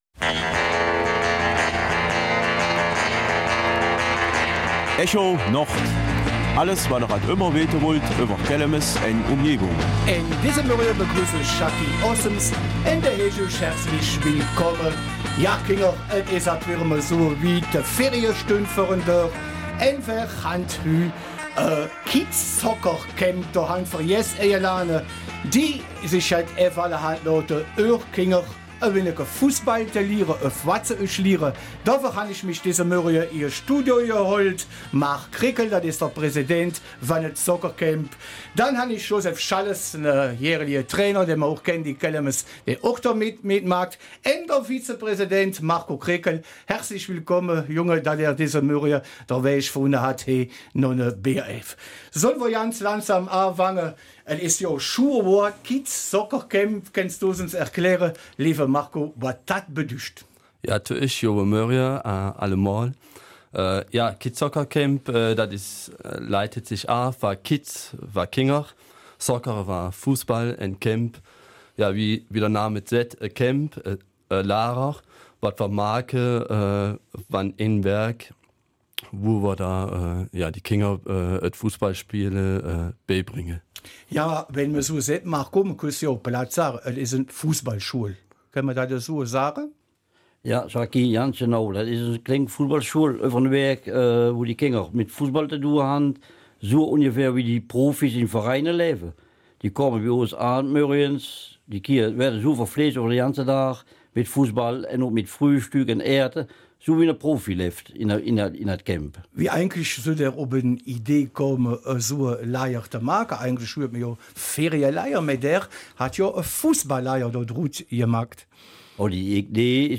Kelmiser Mundart: Kids Soccer Camp in Walhorn